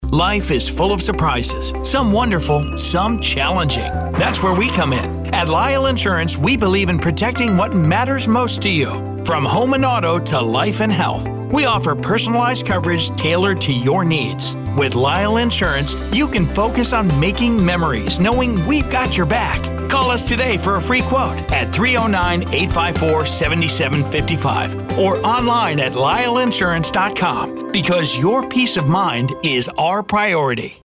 ad samples.
Insurance-Commercial-Uppdated.mp3